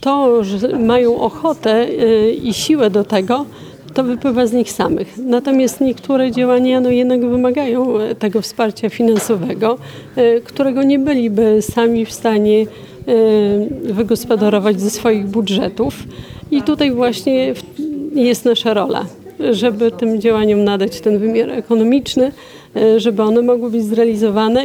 Wicestarosta powiatu łomżyńskiego, Maria Dziekońska podkreśla, że są to zadania realizowane przez społeczników.